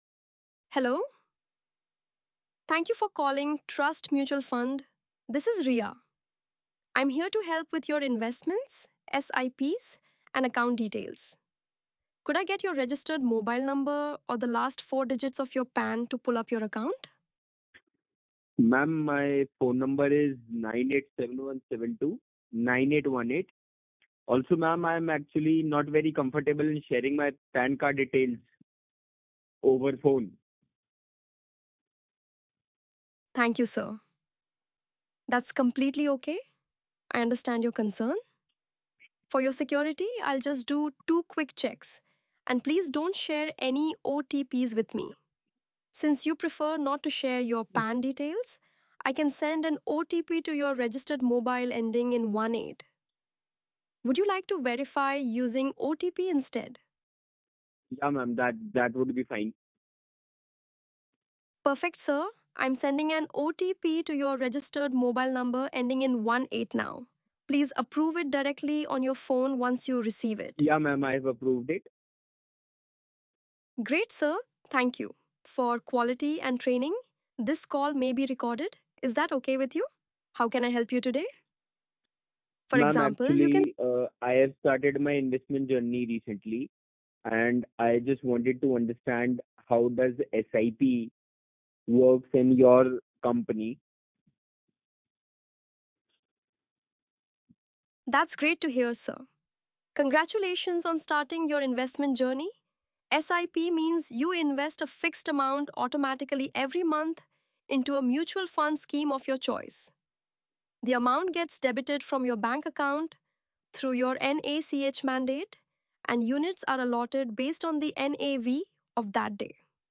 • English Indian
• Female